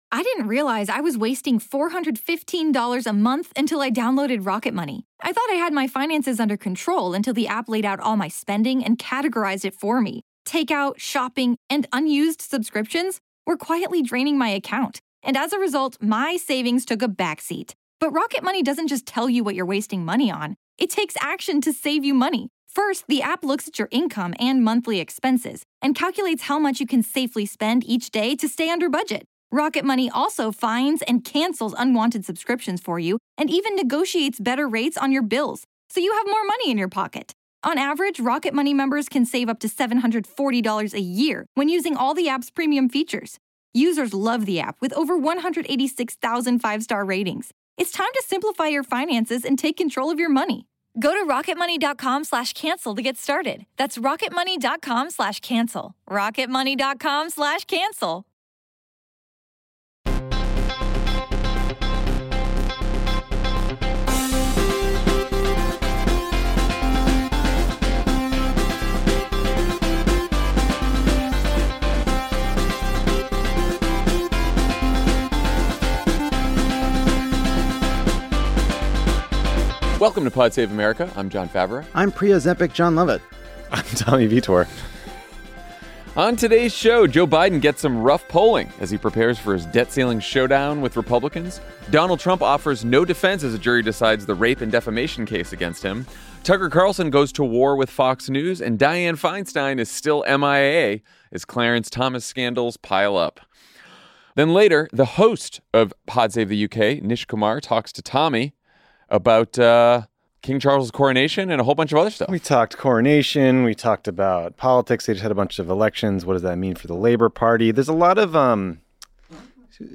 Then later, Pod Save the UK host Nish Kumar talks to Tommy about King Charles’ coronation and UK politics.